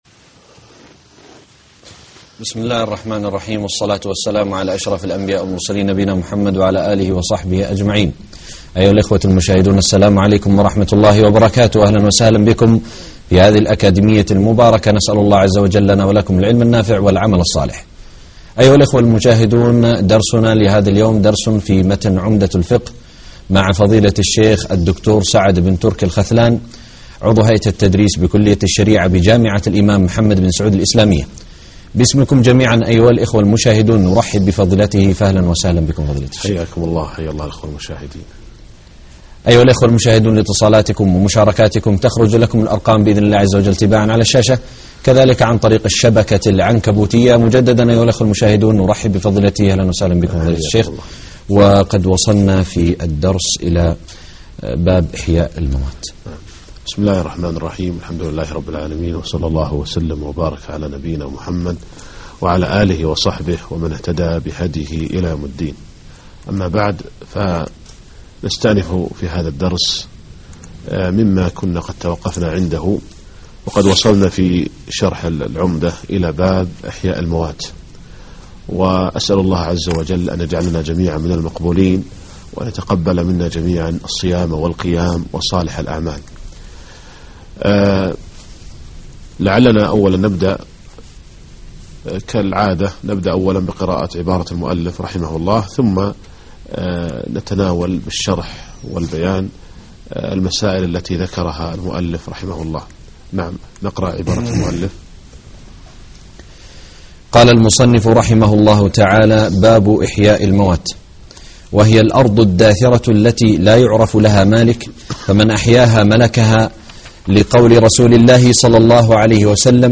الدرس 10 _ باب إحياء الموات